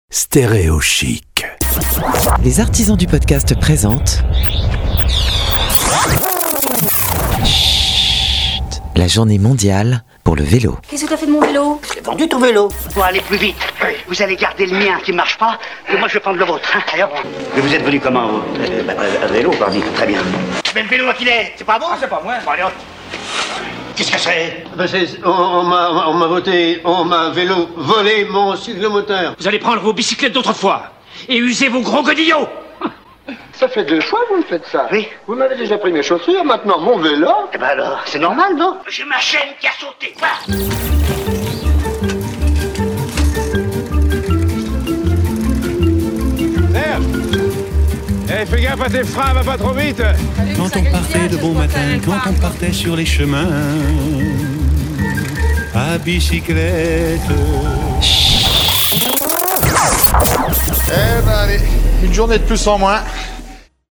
A l'occasion de la Journée Mondiale pour le vélo, le 3 Juin 2021, voici une immersion de 60 secondes avec Schhhhhht produit par les Artisans du Podcast.